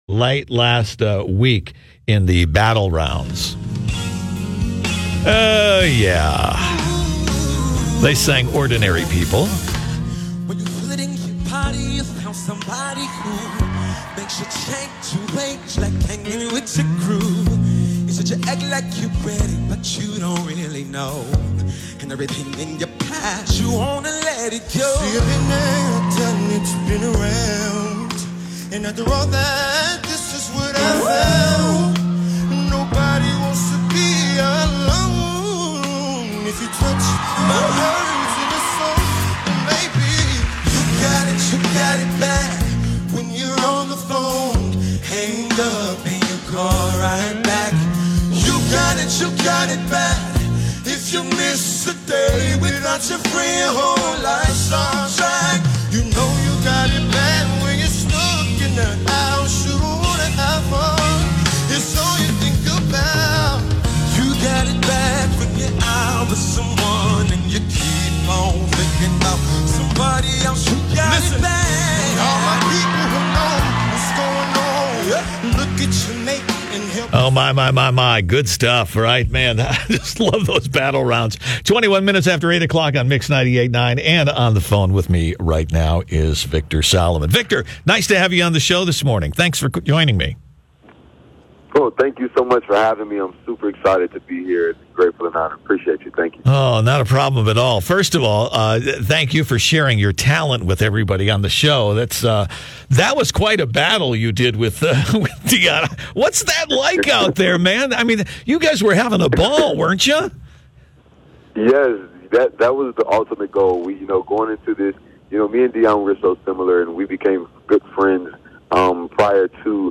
Here’s the revealing interview https